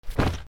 カバンを落とす(mix用素材)
/ J｜フォーリー(布ずれ・動作) / J-10 ｜転ぶ　落ちる
服の上